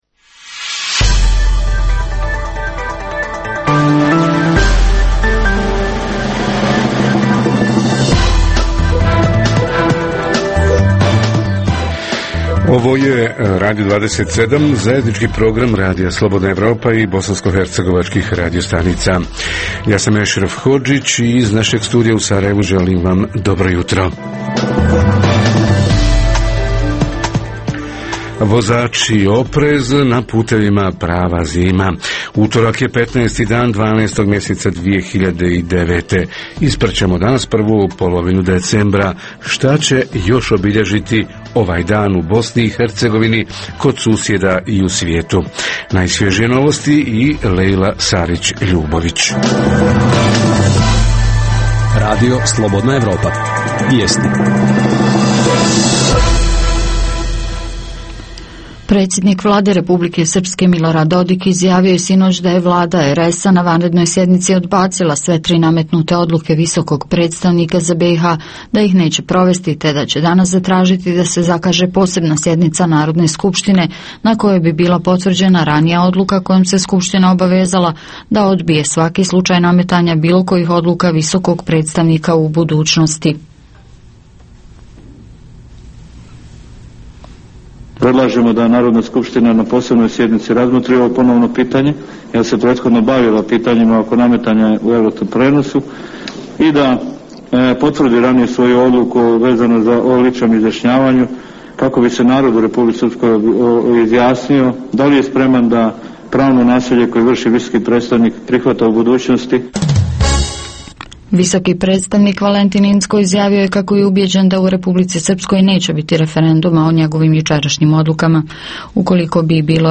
Tema jutra: međuuniverzitetska saradnja u BiH i inozemstvu Reporteri iz cijele BiH javljaju o najaktuelnijim događajima u njihovim sredinama.
Redovni sadržaji jutarnjeg programa za BiH su i vijesti i muzika.